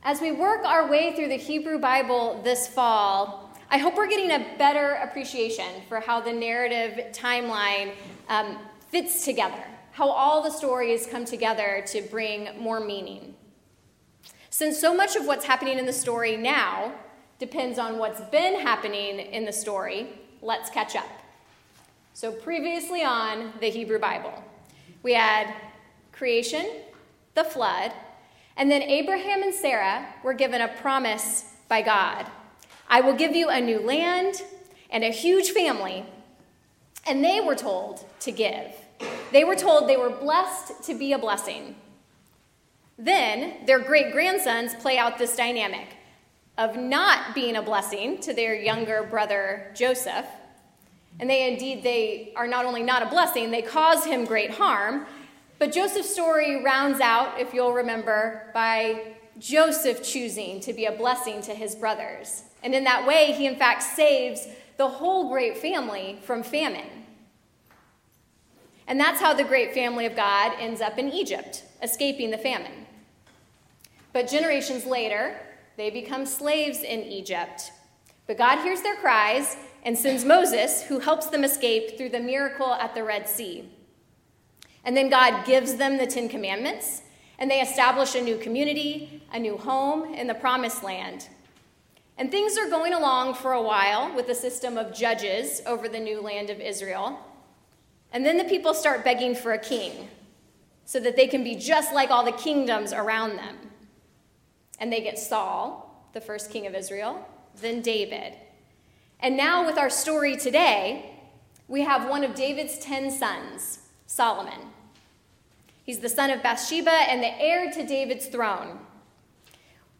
Scripture Lesson 1 Kings 3:4-15 Matthew 6:19-24